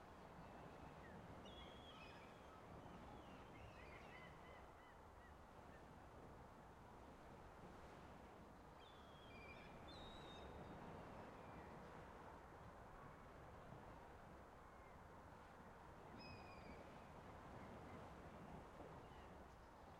sfx_amb_map_zoomedin_ocean.ogg